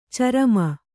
♪ carama